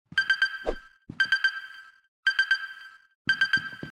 no ammo Meme Sound Effect
no ammo.mp3